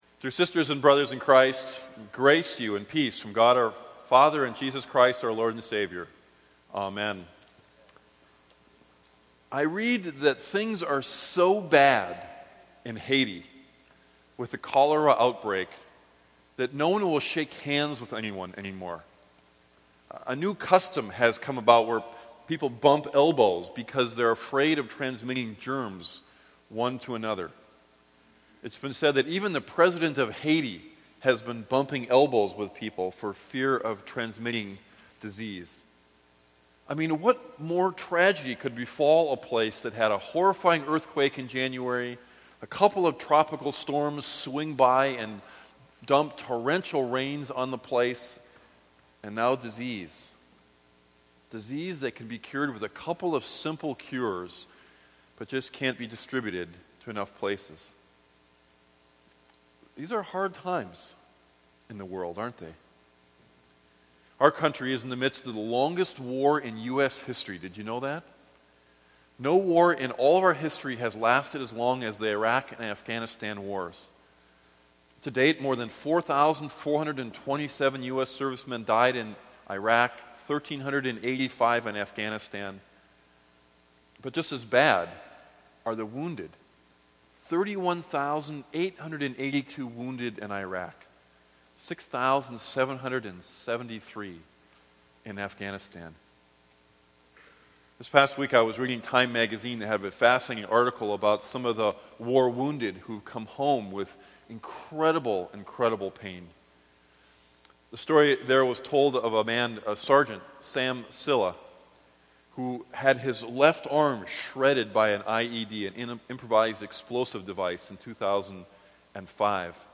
Here is one of my old sermons on Luke 21 entitled ‘Hard Times.’ I pray that in it you might hear God speak a word of comfort to you amidst such hard times.